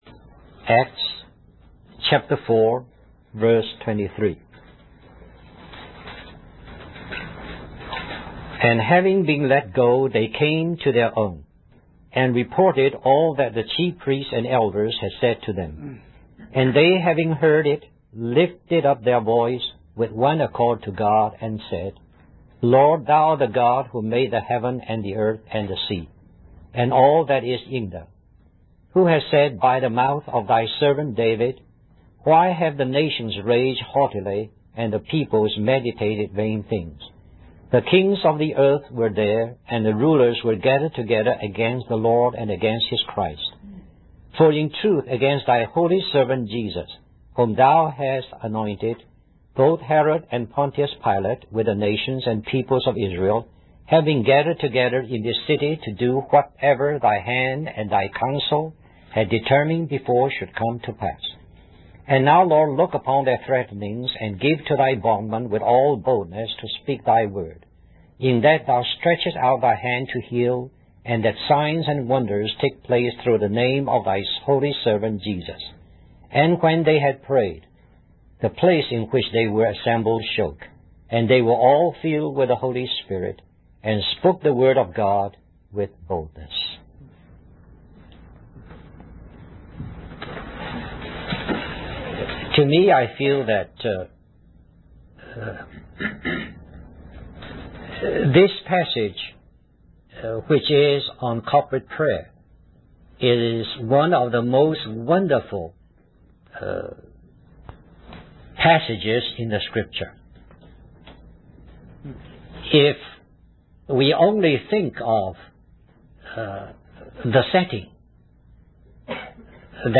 In this sermon, the preacher emphasizes the importance of prayer and being available to the Holy Spirit.